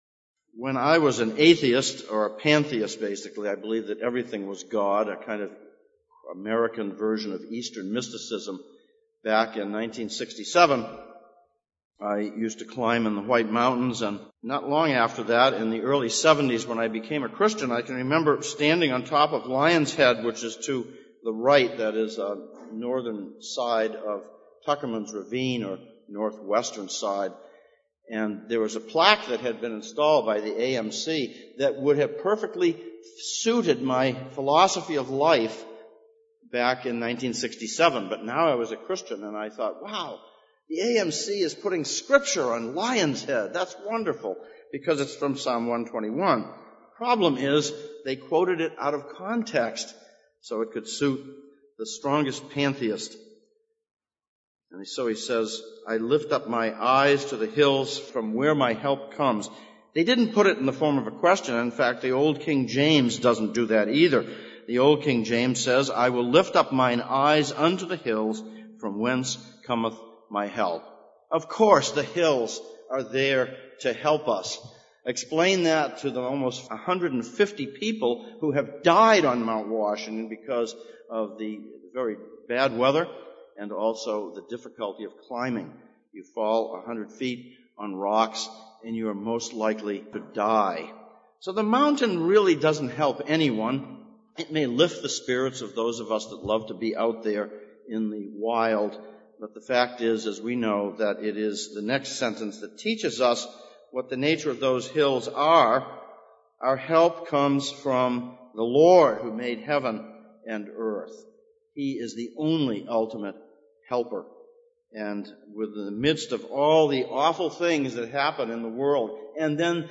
Psalms of Ascents Passage: Psalm 121:1-8, Revelation 7:9-17 Service Type: Sunday Morning « Dealing with Our Differences 1.